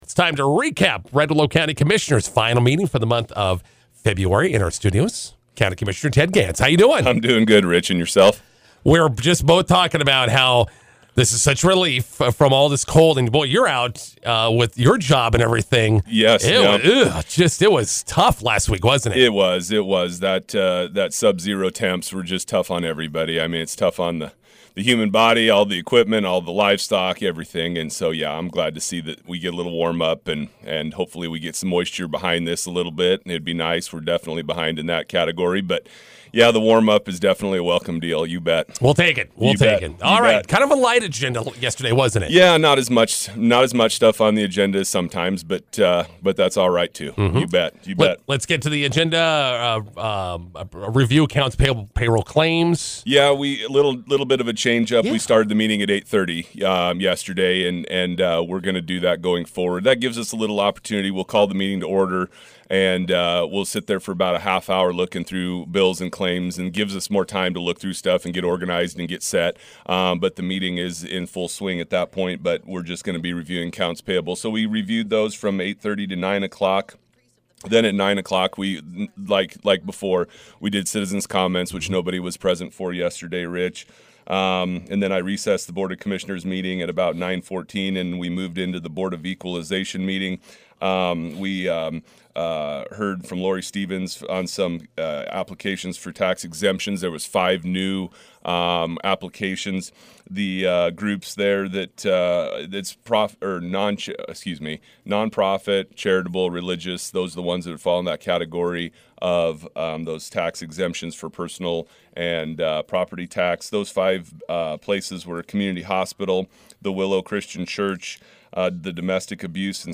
INTERVIEW: Red Willow County Commissioners meeting recap with County Commissioner Ted Gans. | High Plains Radio
INTERVIEW: Red Willow County Commissioners meeting recap with County Commissioner Ted Gans.